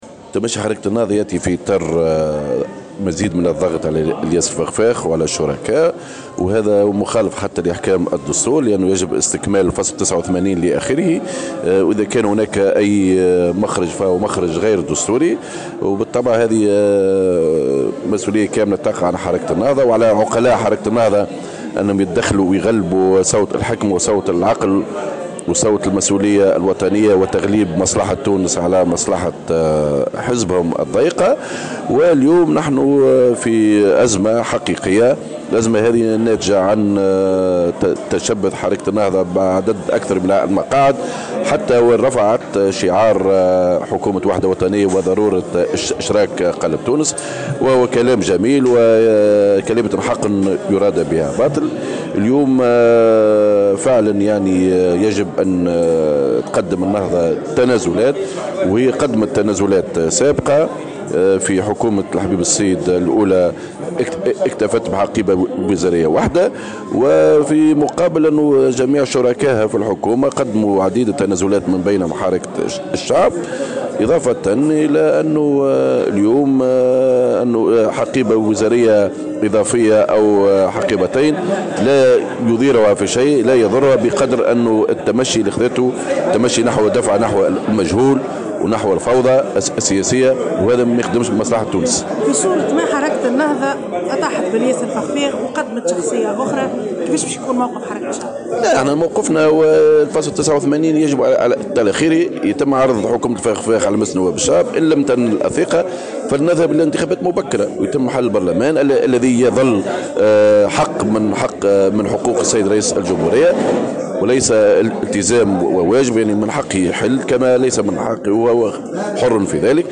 أكد القيادي في حركة الشعب خالد الكريشي في تصريح لمراسلة الجوهرة "اف ام" أن التمشي الذي إختارته النهضة يأتي في اطار مزيد من الضغط على الفخفاخ وعلى الشركاء السياسيين معتبرا أن هذا التمشي مخالف لأحكام الدستور.